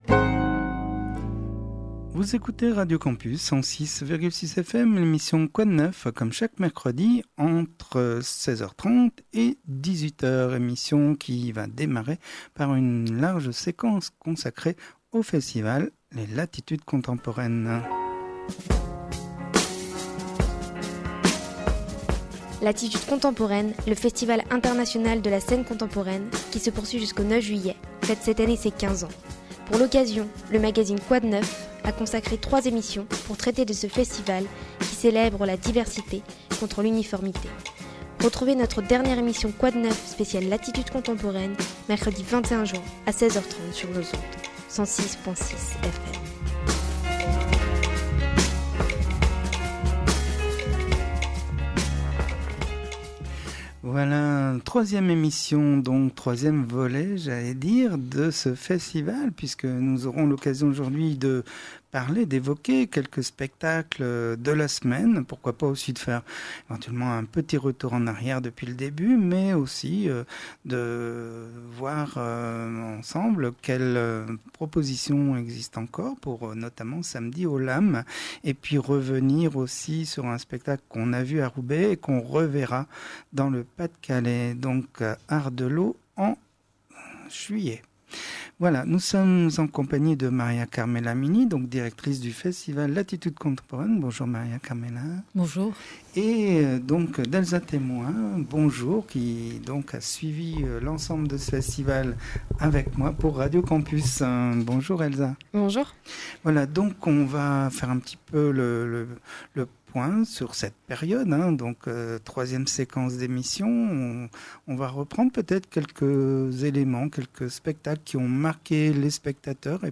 mission de cl�ture avec en studio ce jour